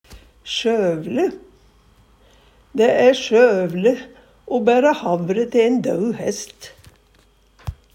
DIALEKTORD PÅ NORMERT NORSK sjøvele gagnlaust, bortkasta, nytteslaust, ikkje verdt bryet Eksempel på bruk Dæ æ sjøvele o bæra havre te ein dau hest.